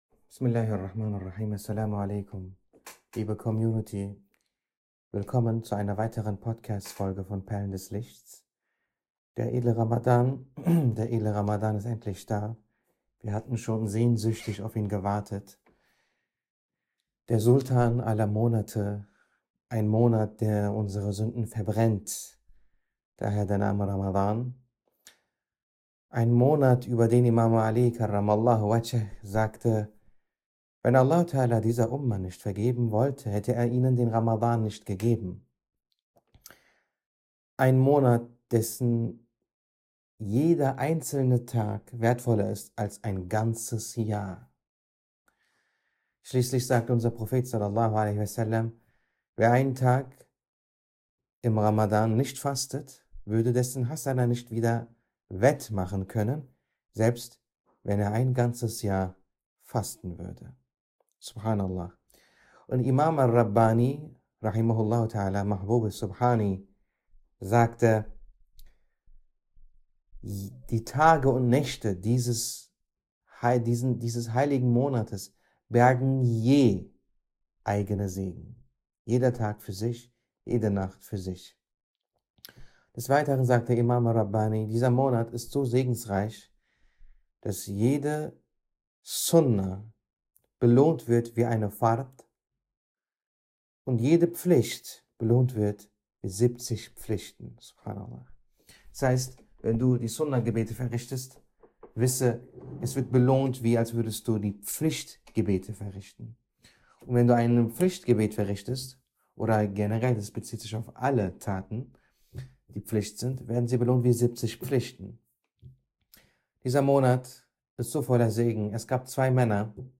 Stil & Sprache: Sanft, poetisch und tief.